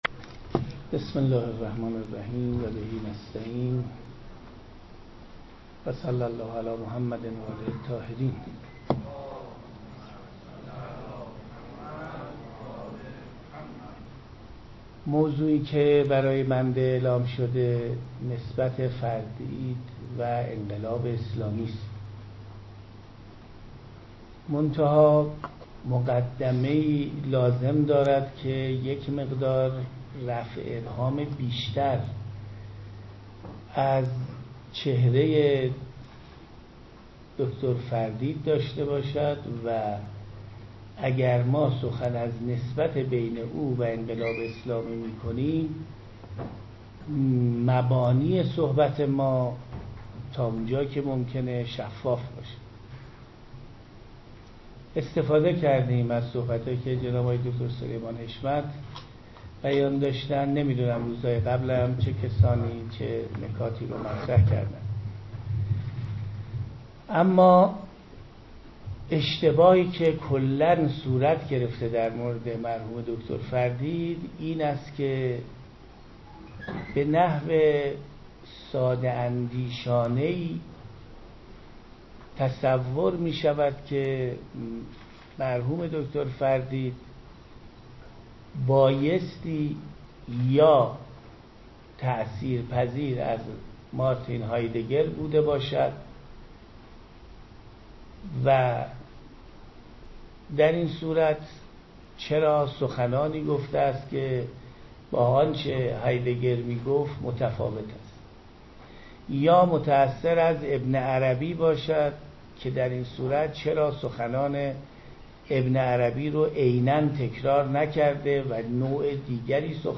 سخنرانی
در همایش بررسی و نقد آرا دکتر سید احمد فردید در دانشکده ادبیات و زبانهای دانشگاه علامه طباطبایی